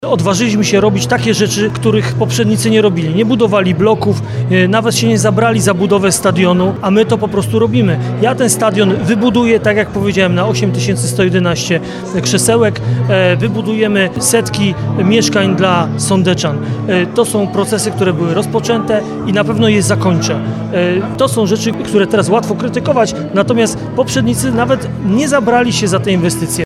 Okazją ku temu była piątkowa (15.12) konwencja.